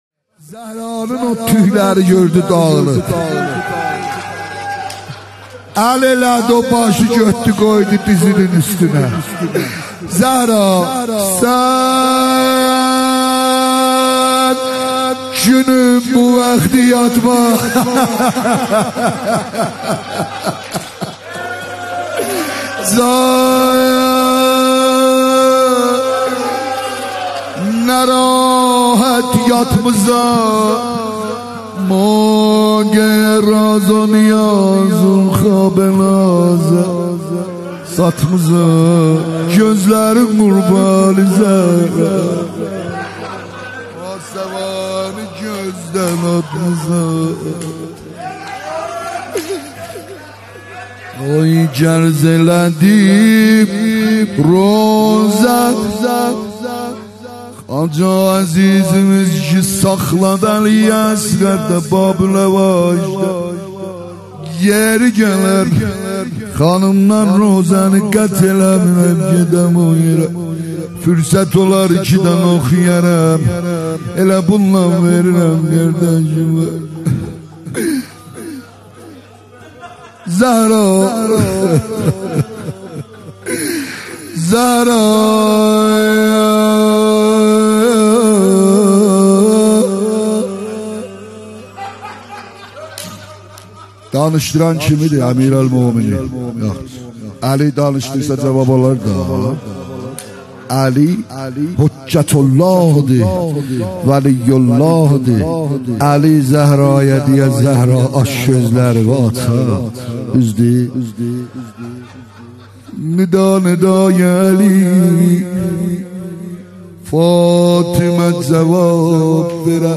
روضه حضرت زهرا سلام الله علیها